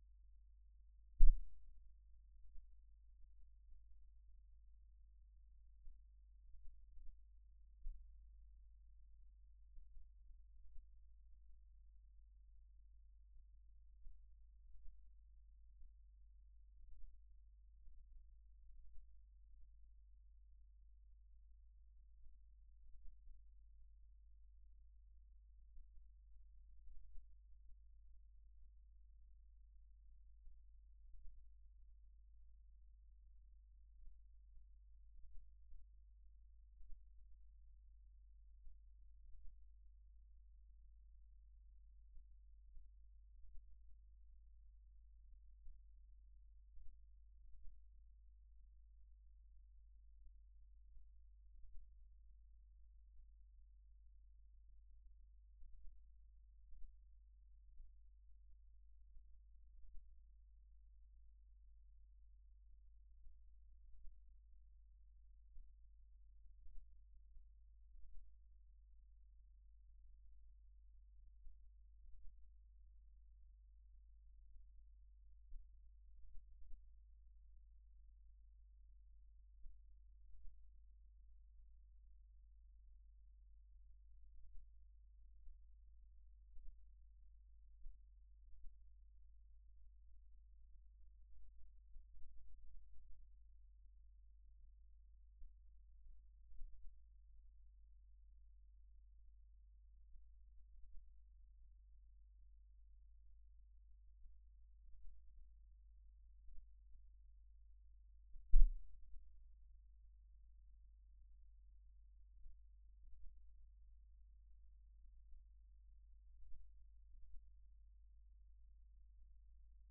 Source file: 05MAR15-0000_IC100-RDF.wav 2015-03-05 00:00 to 04:00 at OpenLab st. 01 - Cumiana (TO), N Italy Stereo file acquired with two orthogonal induction coils: NS on left channel, EW on right one.